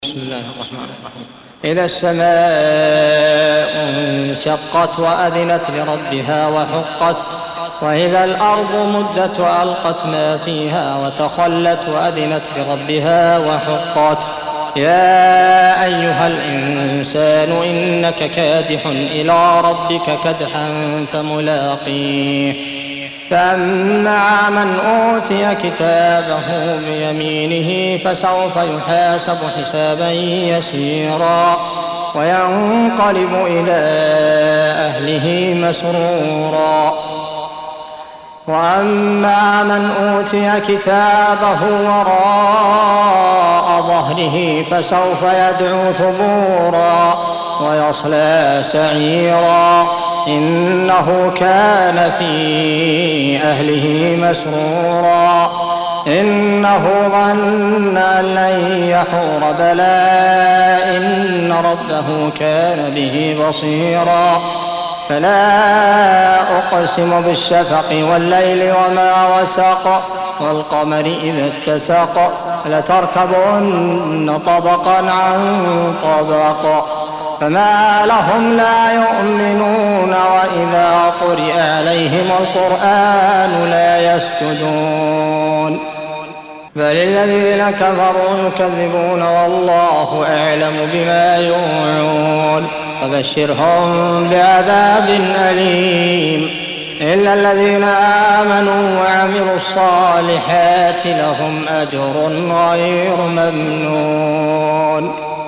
84. Surah Al-Inshiq�q سورة الإنشقاق Audio Quran Tarteel Recitation
Surah Sequence تتابع السورة Download Surah حمّل السورة Reciting Murattalah Audio for 84. Surah Al-Inshiq�q سورة الإنشقاق N.B *Surah Includes Al-Basmalah Reciters Sequents تتابع التلاوات Reciters Repeats تكرار التلاوات